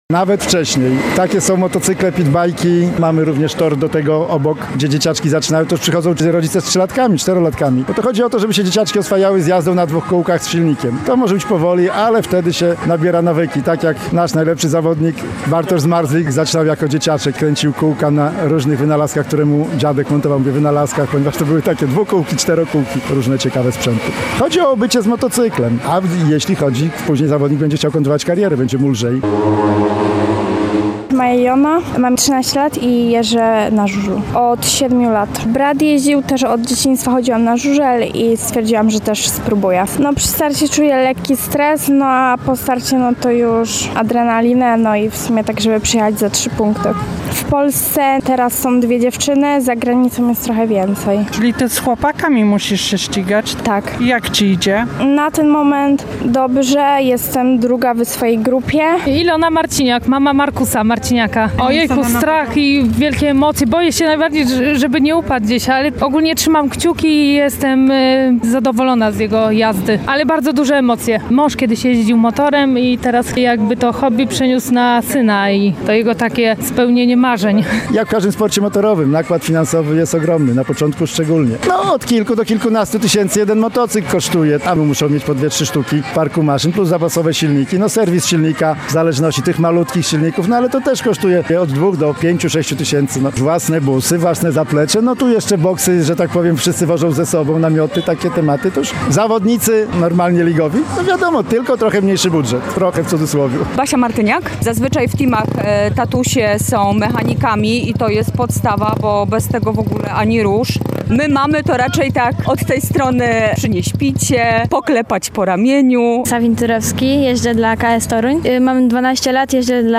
Ryk silników i zapach metanolu czuć było na ulicy Kresowej w Lublinie. Młodzi adepci mini żużla wzięli udział w zawodach – Towarzyskim Turnieju Par Okręgu Lubelskiego.